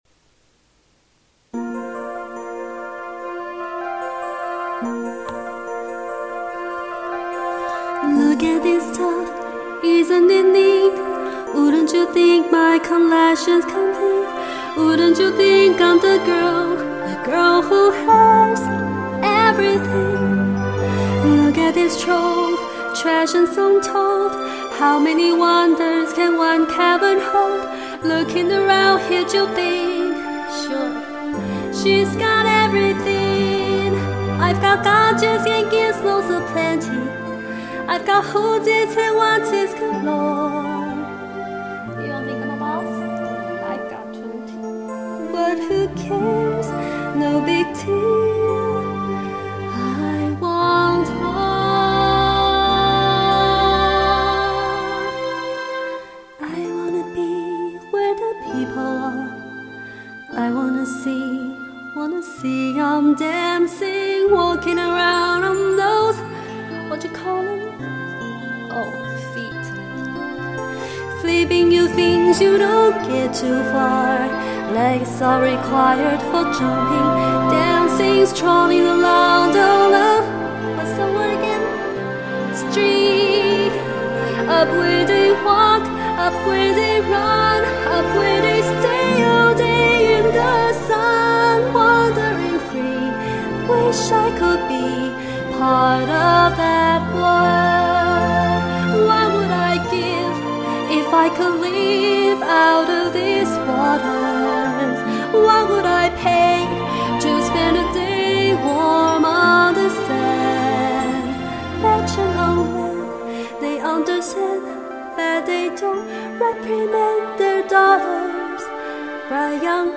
有點像蔡依林 這樣講你會不會生氣？